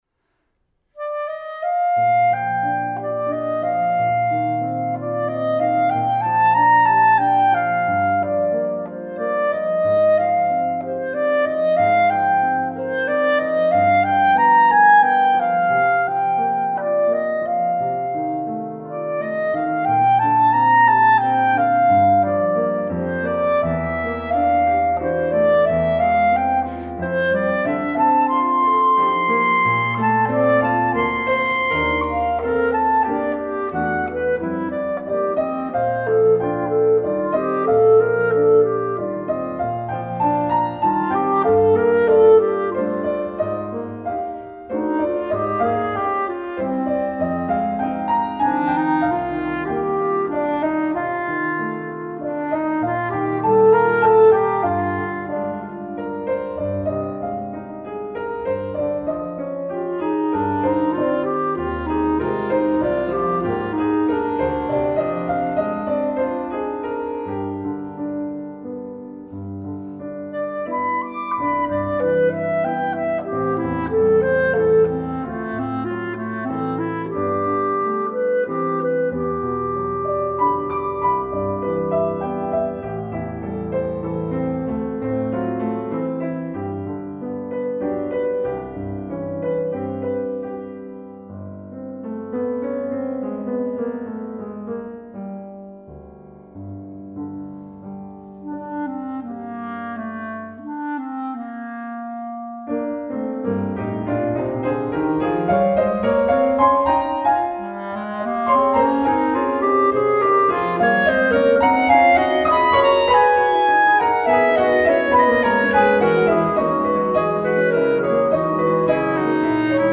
1. Moderately